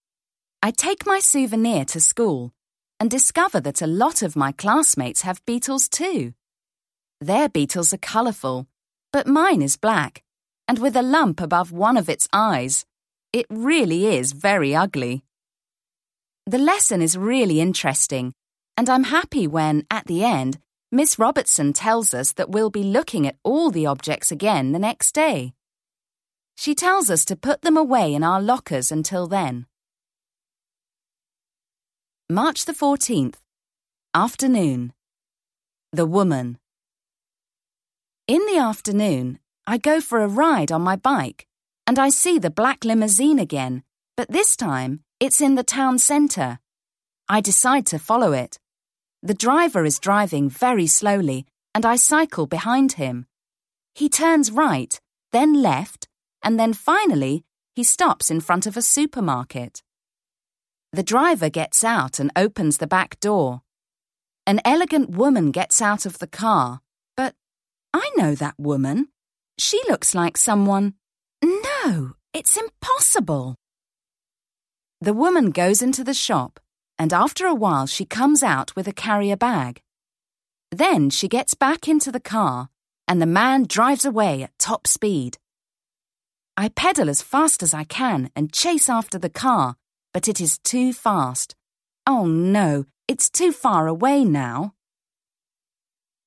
The Egyptian Souvenir (EN) audiokniha
Ukázka z knihy